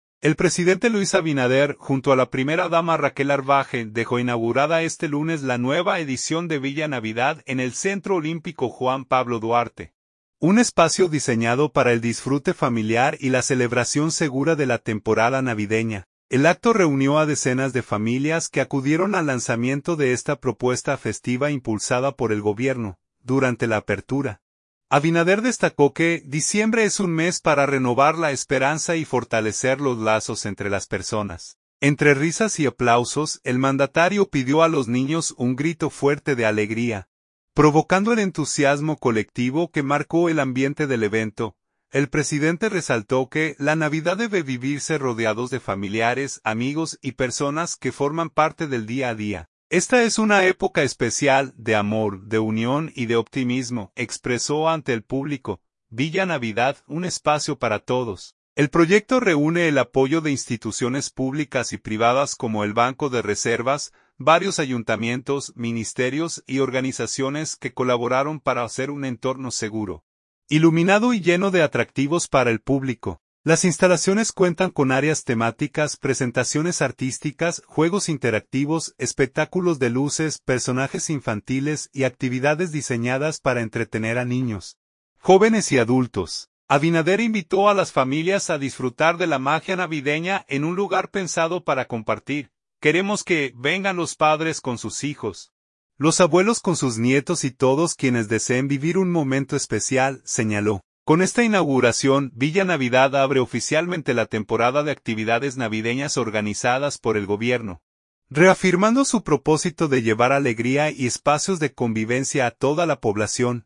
Entre risas y aplausos, el mandatario pidió a los niños “un grito fuerte de alegría”, provocando el entusiasmo colectivo que marcó el ambiente del evento.